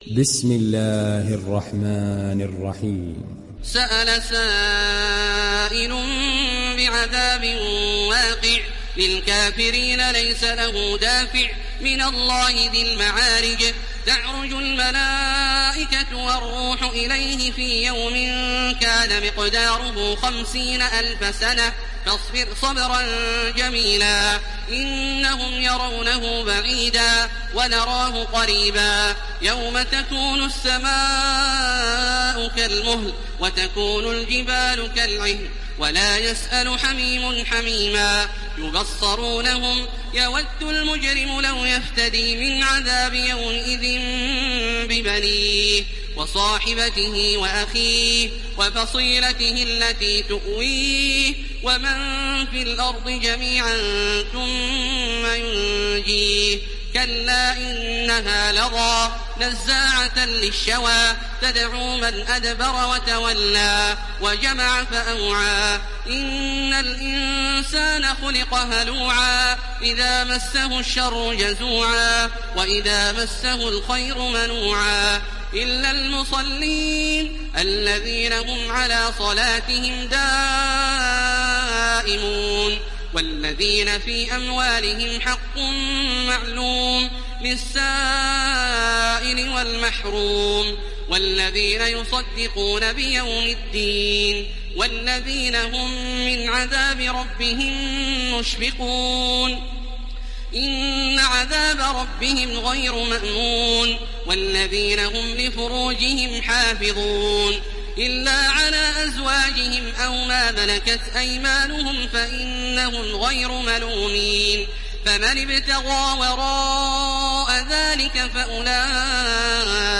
Télécharger Sourate Al Maarij Taraweeh Makkah 1430